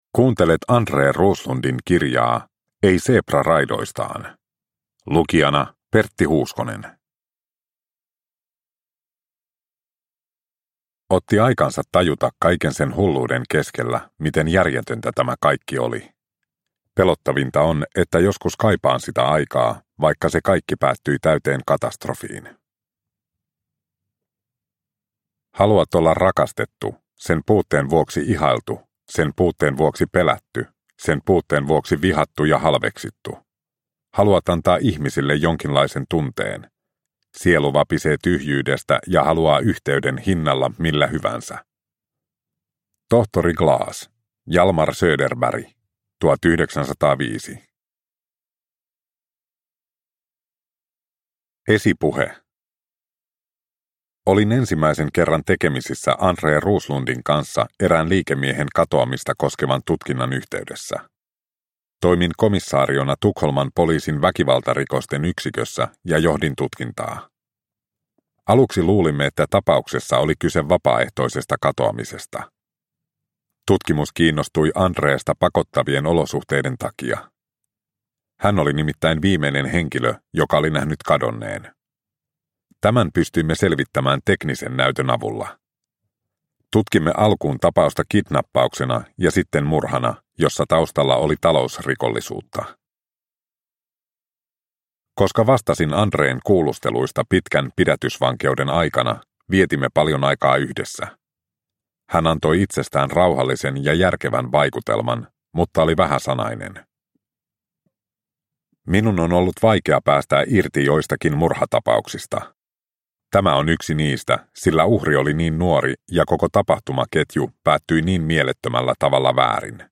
Ei seepra raidoistaan – Ljudbok – Laddas ner